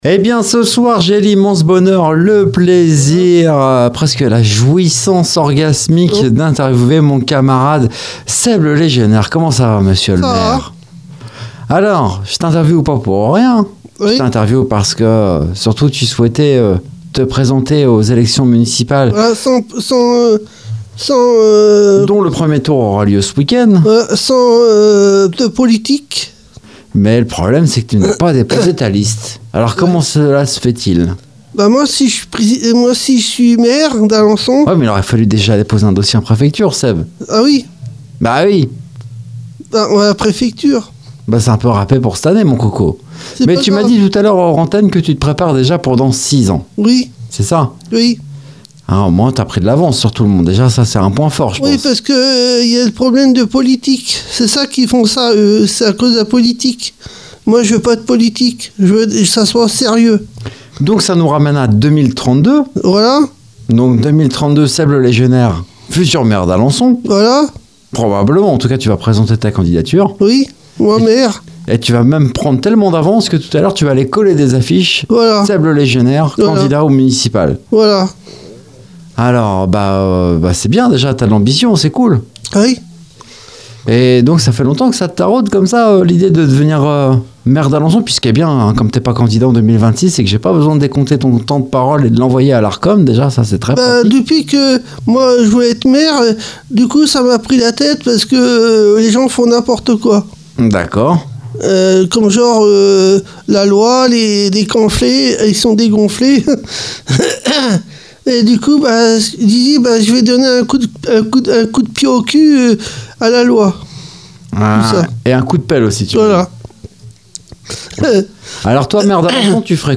Dans cette interview, il revient sur les raisons de cet engagement inattendu, sa vision pour la ville et les idées qu’il aimerait défendre s’il venait à se lancer pleinement dans la bataille politique locale.
Une conversation à la fois sérieuse et décalée, à découvrir dans ce podcast. culture local légionnaire Indien